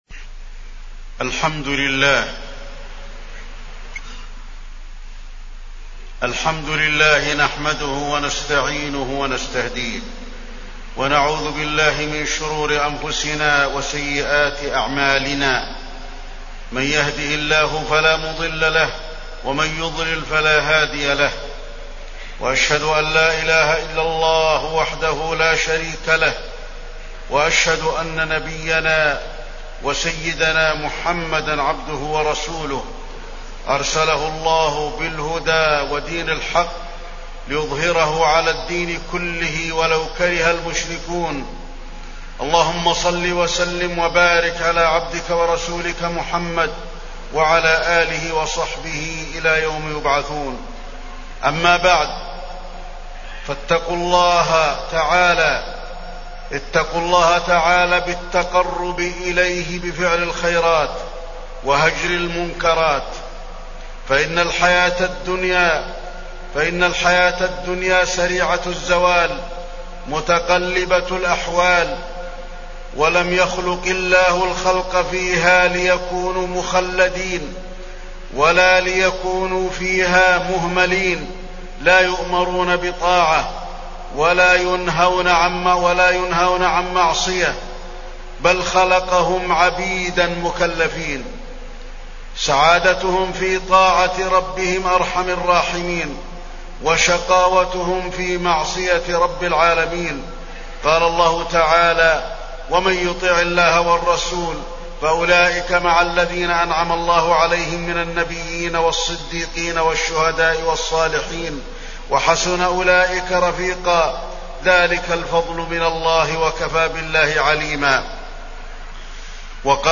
تاريخ النشر ١ رجب ١٤٢٩ هـ المكان: المسجد النبوي الشيخ: فضيلة الشيخ د. علي بن عبدالرحمن الحذيفي فضيلة الشيخ د. علي بن عبدالرحمن الحذيفي التحذير من الغفلة